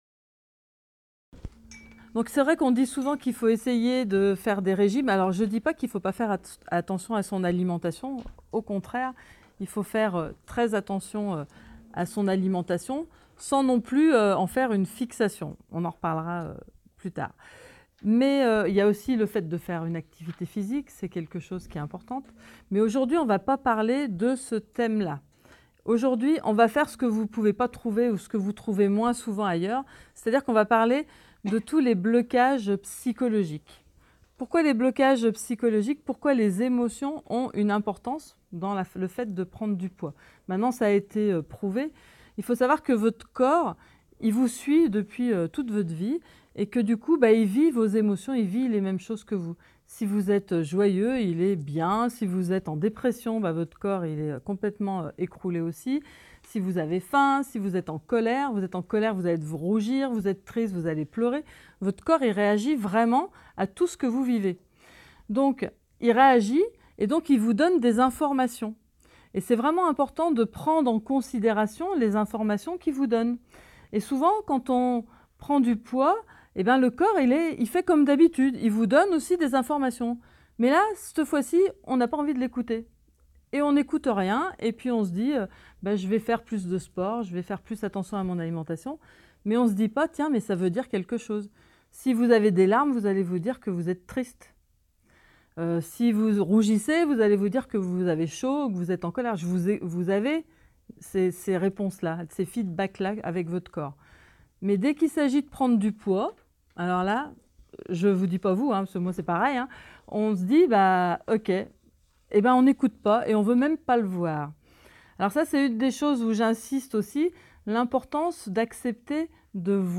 Extrait audio, d'une conférence qui explique pourquoi il est important de s'accepter telle que l'on est avant de chercher à maigrir.
Extrait de la conférence "Mincir autrement..."